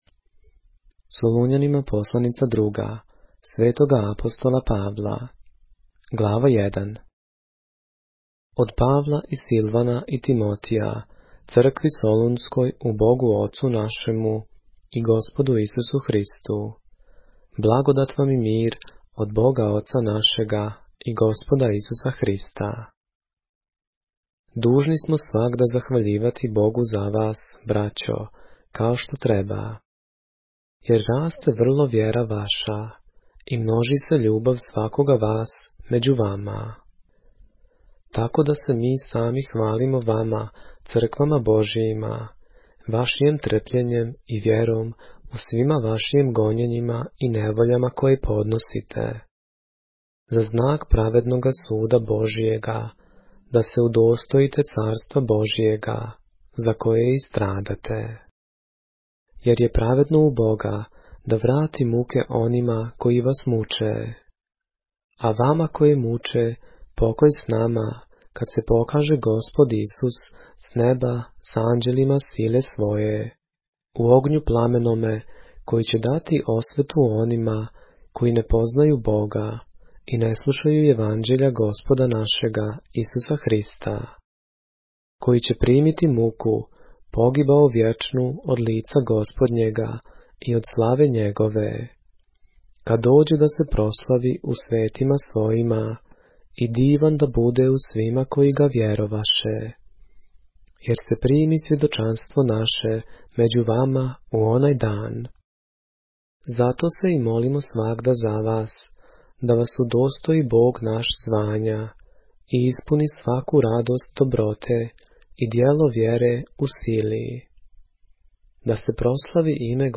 поглавље српске Библије - са аудио нарације - 2 Thessalonians, chapter 1 of the Holy Bible in the Serbian language